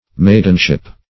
Maidenship \Maid"en*ship\